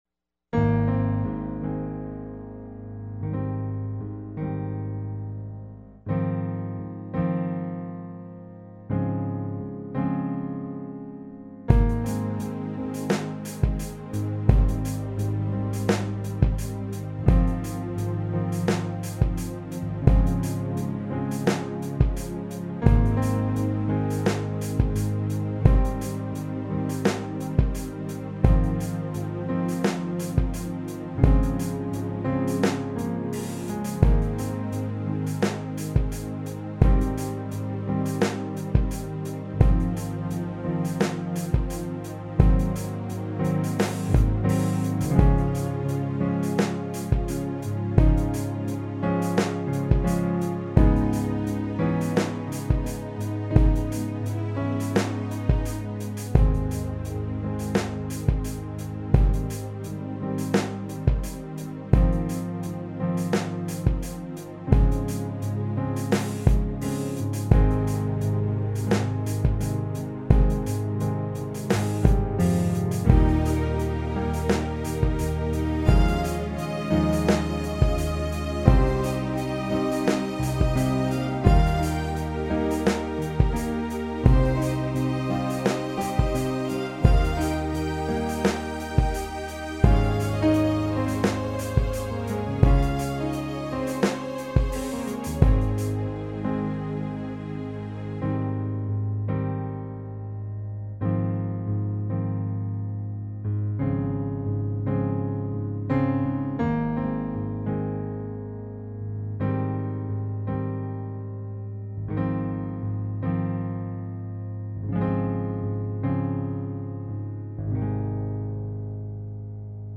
At this point I'd moved past the 4-track recorder and was recording everything digitally onto the PC.